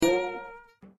metal_damage_02.ogg